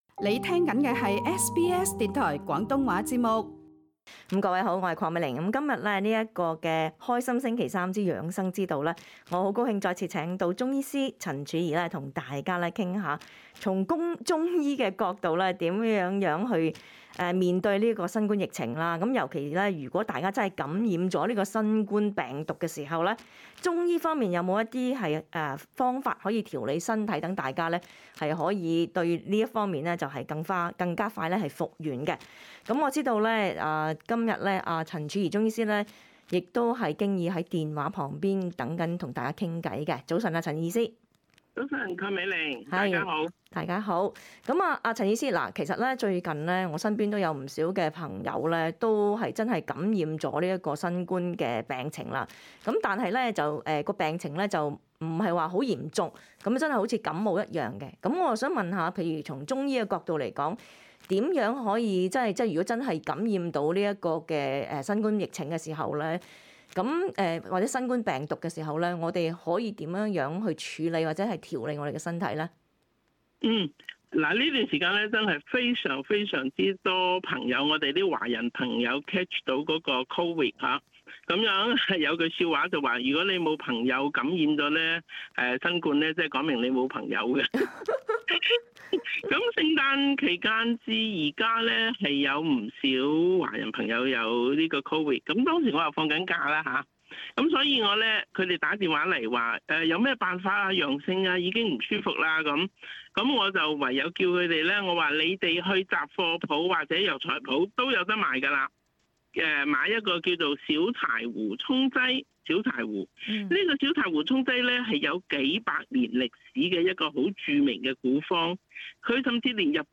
wedtalkback12jan.mp3